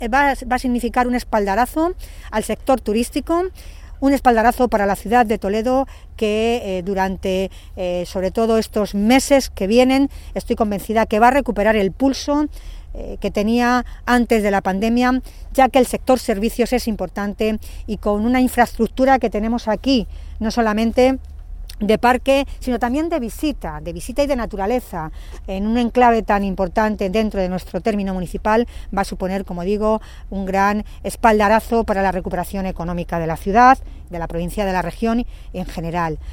AUDIOS. Milagros Tolón, alcaldesa de Toledo
milagros-tolon_vivista-a-puy-du-fou_1.mp3